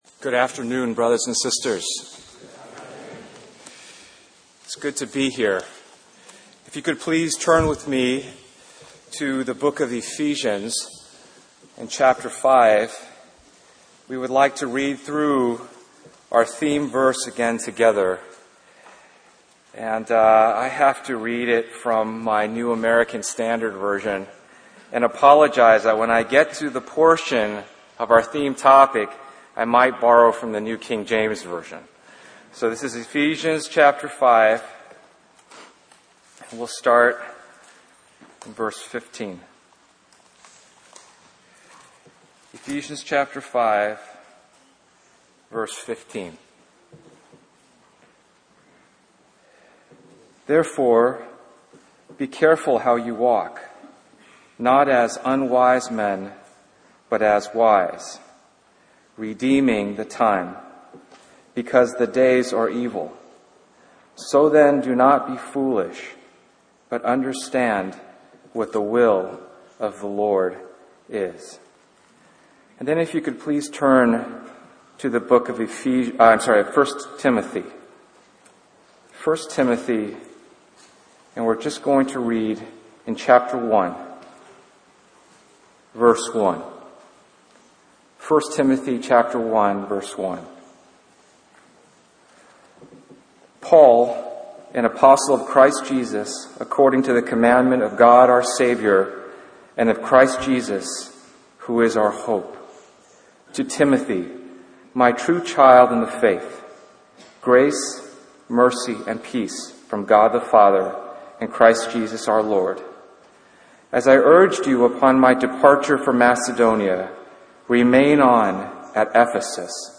Harvey Cedars Conference We apologize for the poor quality audio